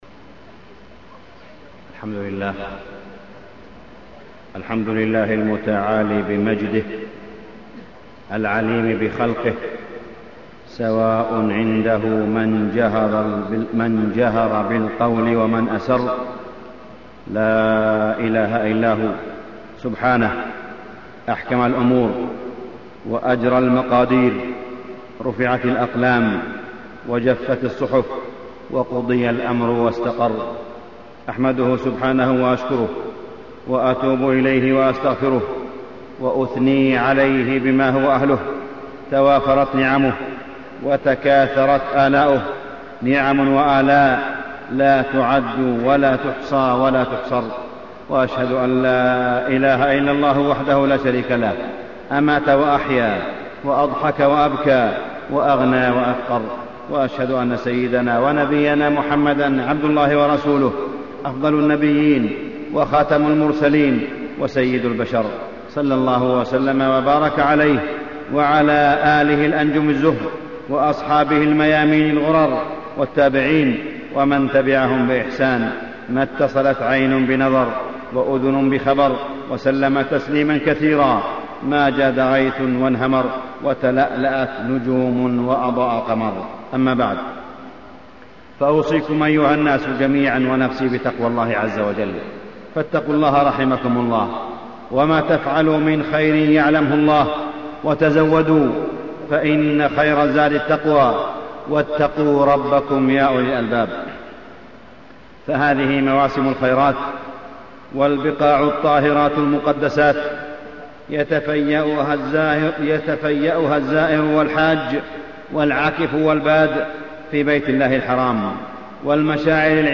تاريخ النشر ٢٤ ذو القعدة ١٤٢٧ هـ المكان: المسجد الحرام الشيخ: معالي الشيخ أ.د. صالح بن عبدالله بن حميد معالي الشيخ أ.د. صالح بن عبدالله بن حميد الإنتفاع في حسن الإستماع